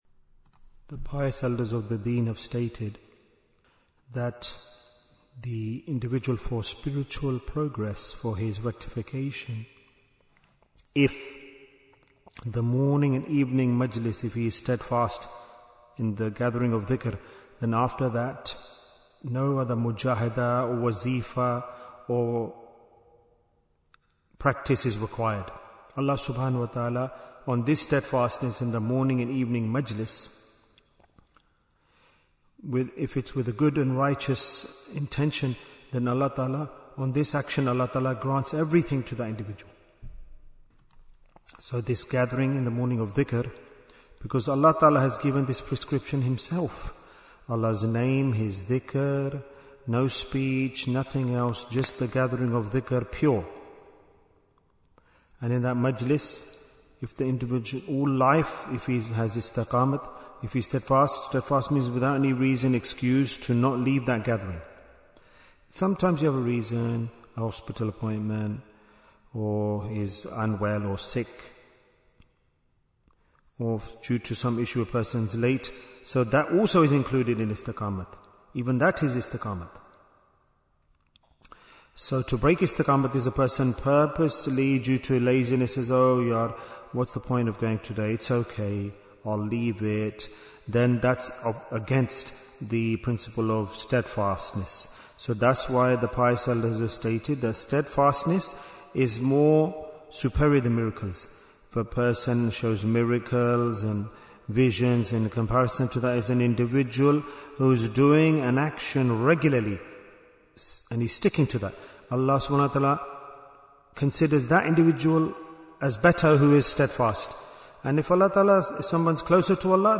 - Part 3 Bayan, 49 minutes 13th January, 2026 Click for Urdu Download Audio Comments Why is Tazkiyyah Important? - Part 3 Being steadfast upon the Dhikr gatherings, refers to not abandoning the gathering without a valid excuse.